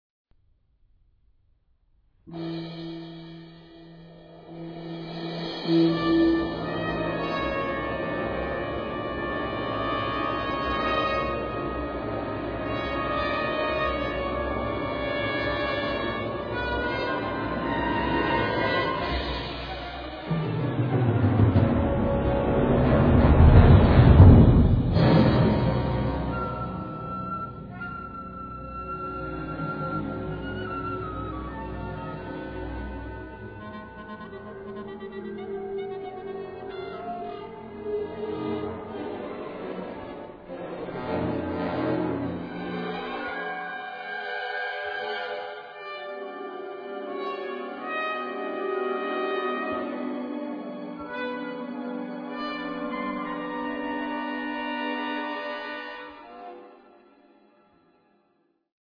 Classical
Avant Garde